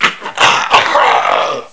ca9d35c2bc assets / psp / nzportable / nzp / sounds / zombie / d2.wav Steam Deck User 08712ab263 PSP/CTR: Also make weapon and zombie sounds 8bit 2023-02-20 17:40:04 -05:00 27 KiB Raw History